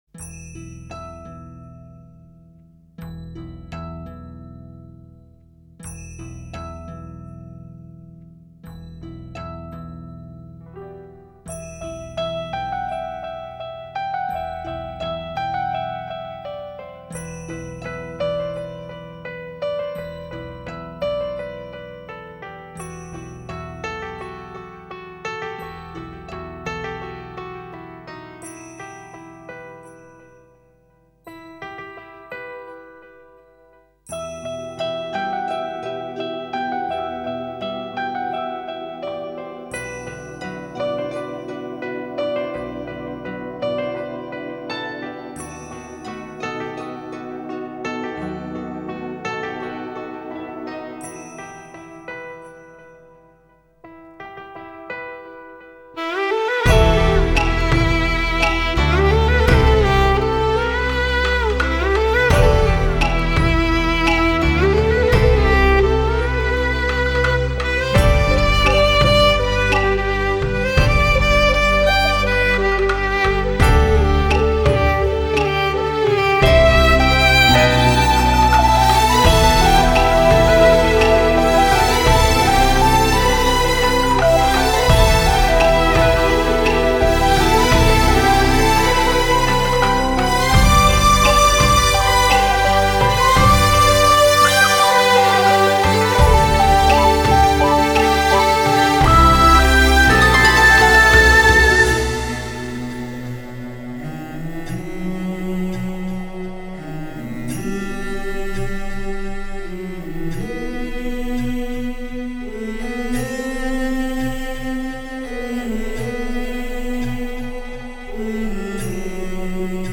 Lyricist: Instrumental